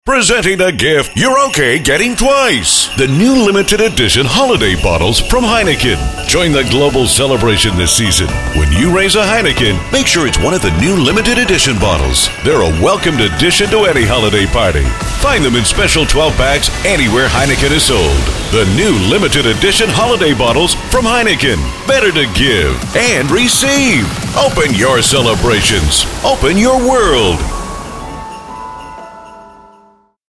Affordable Professional Non Union Male Voiceover Talent
HEINEKEN HOLIDAY COMM 2013 DEF.mp3